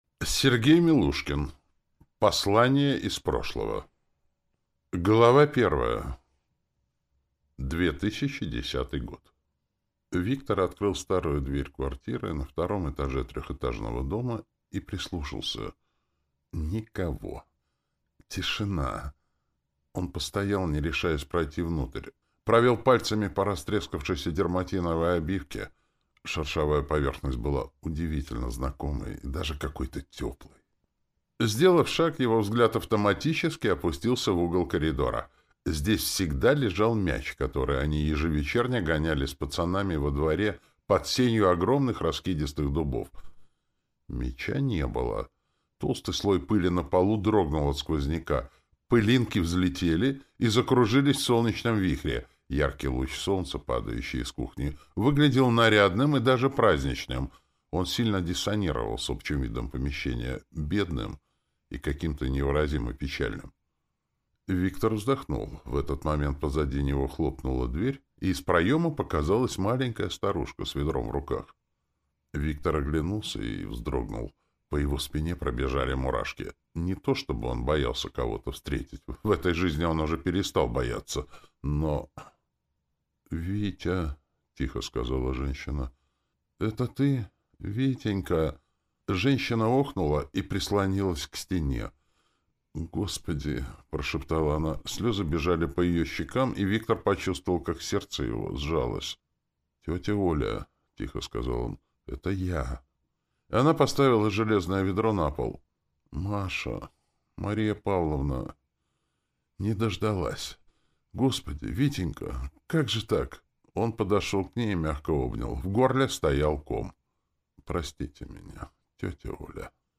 Аудиокнига Послание из прошлого | Библиотека аудиокниг